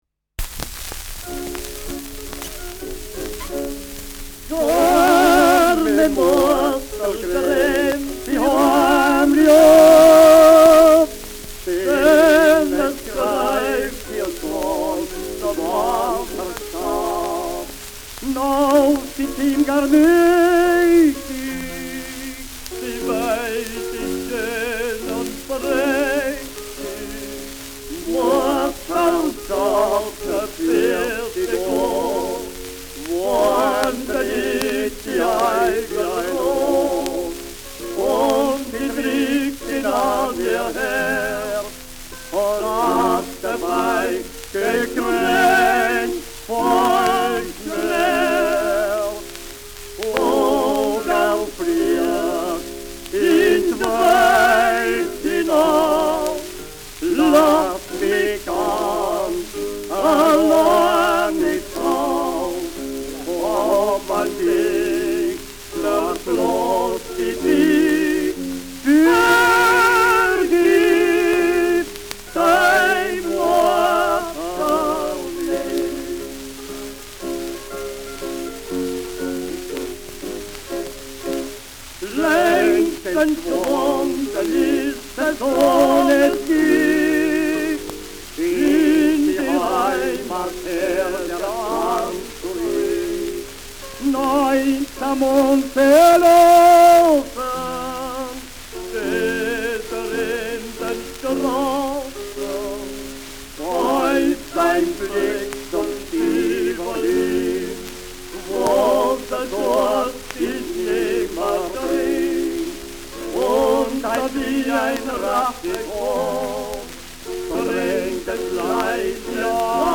Schellackplatte
präsentes Rauschen : präsentes Knistern : gelegentliches „Schnarren“ : leichtes Leiern
Nachtschwärmer-Terzett (Interpretation)